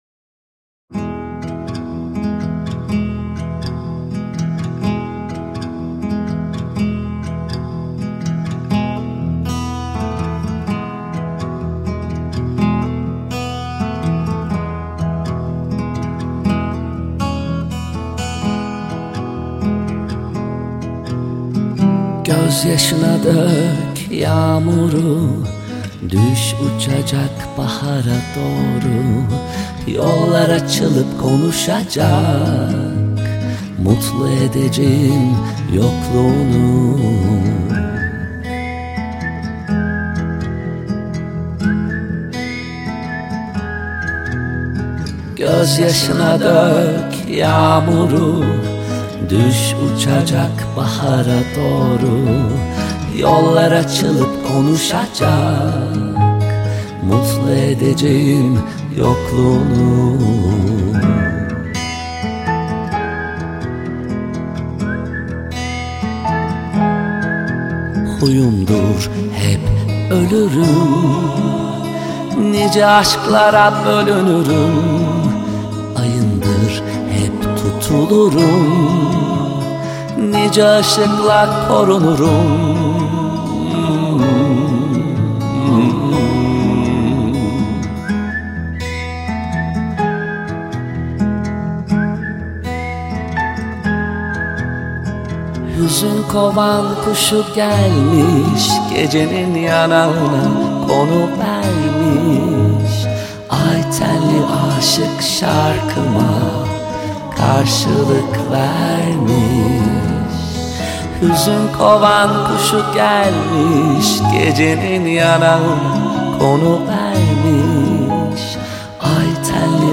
آهنگ جدید موزیک ترکی